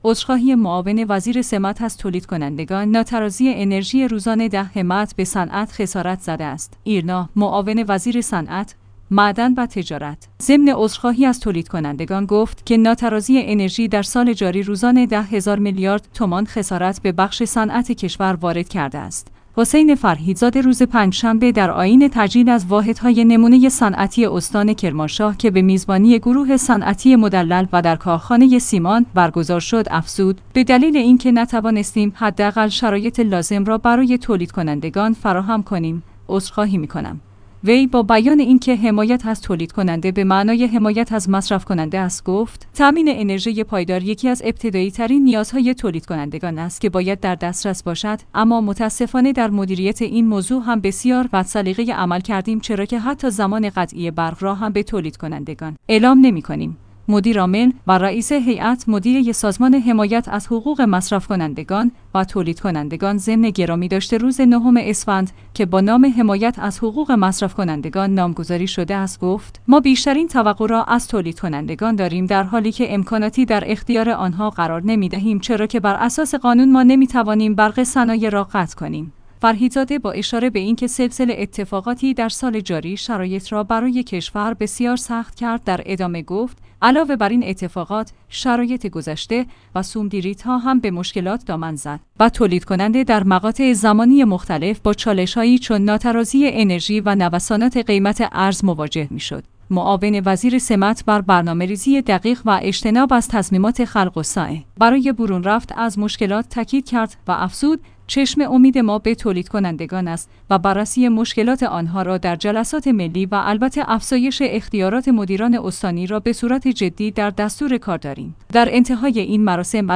ایرنا/معاون وزیر صنعت، معدن و تجارت، ضمن عذرخواهی از تولیدکنندگان گفت که ناترازی انرژی در سال جاری روزانه ۱۰ هزار میلیارد تومان خسارت به بخش صنعت کشور وارد کرده است. حسین فرهیدزاده روز پنجشنبه در آیین تجلیل از واحدهای نمونه صنعتی استان کرمانشاه که به میزبانی گروه صنعتی مدلل و در کارخانه سیمان برگزار